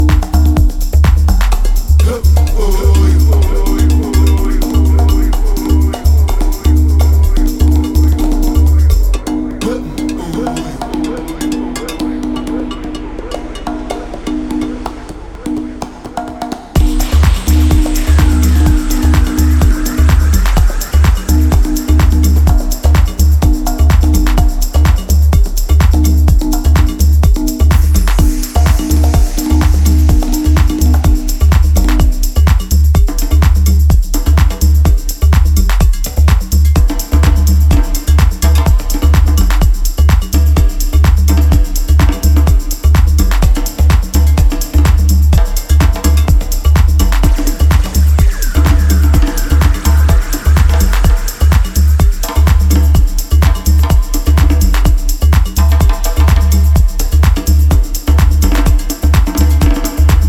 • Afro House